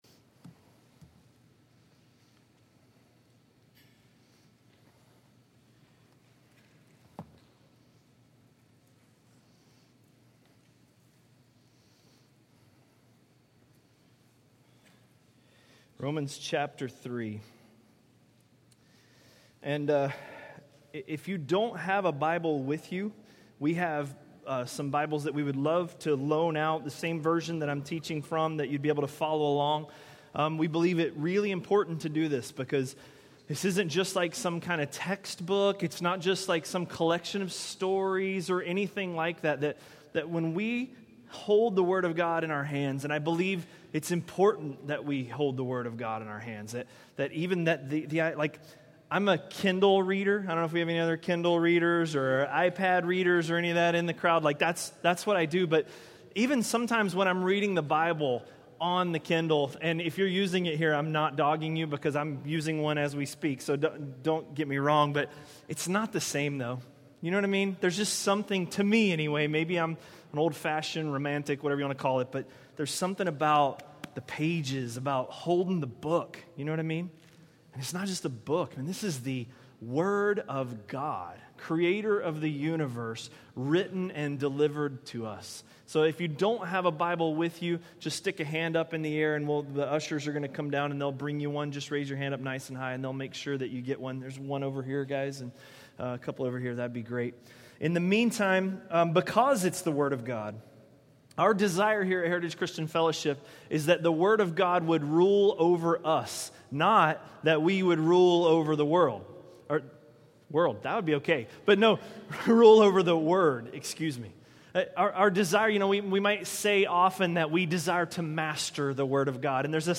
A message from the series "Romans." Romans 3:19–3:21